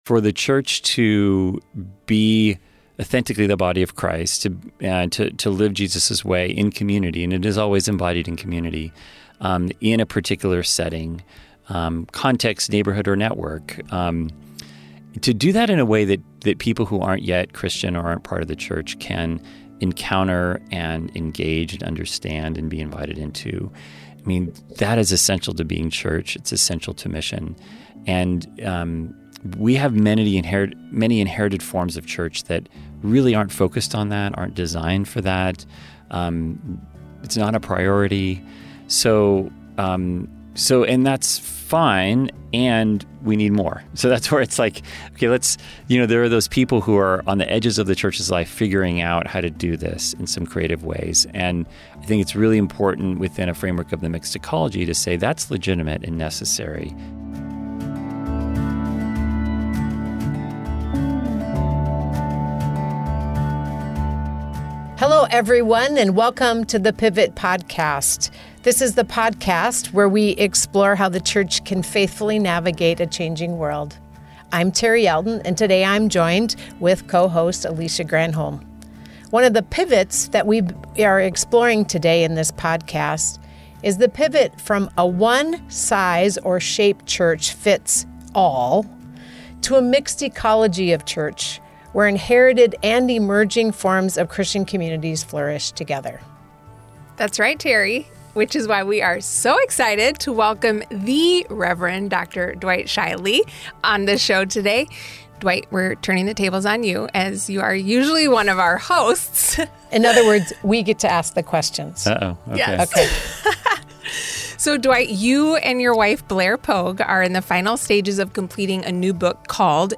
Join us for a conversation that could reshape your understanding of what it means to be and do church in the 21st century.